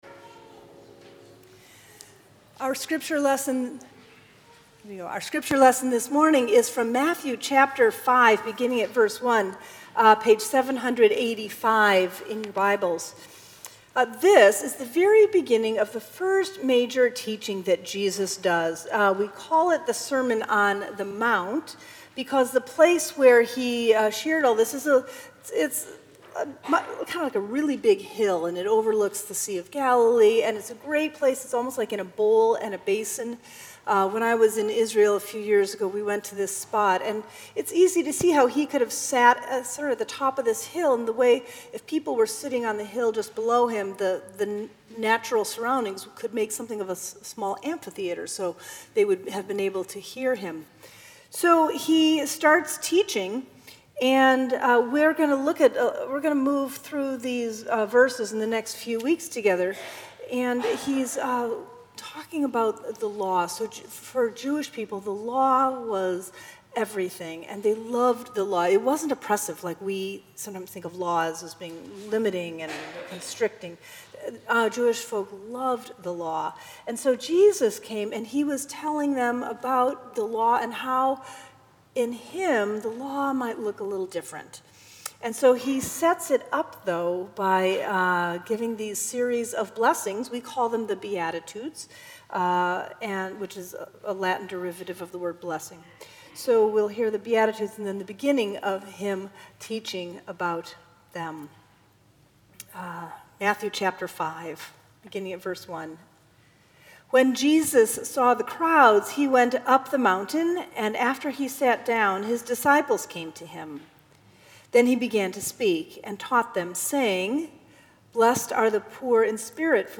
Sermons at Union Congregational Church
February 5, 2017 Fifth Sunday after Epiphany